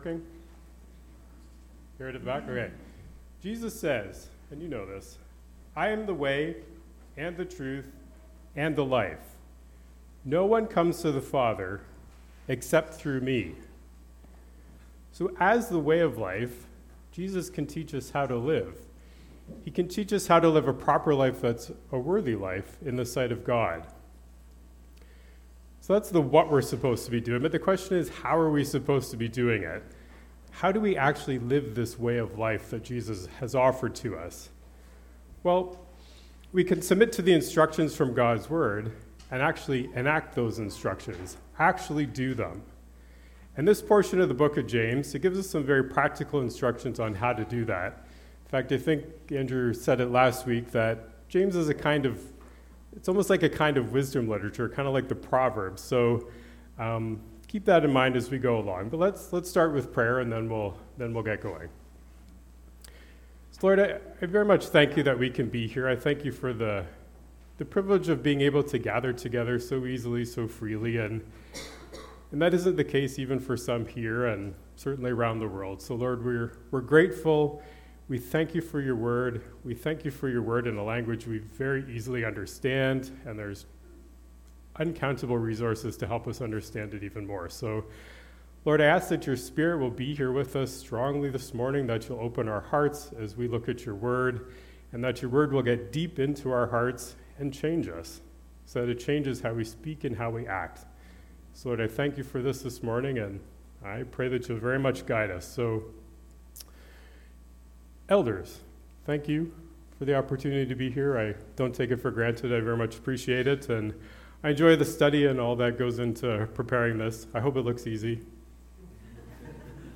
Sermon Audio and Video Faith in Action - Part 3: Hear it, then Do it!